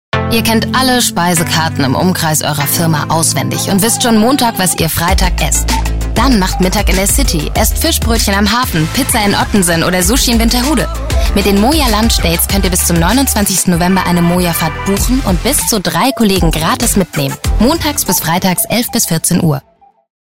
sehr variabel
Jung (18-30)
Schwäbisch
Commercial (Werbung), Overlay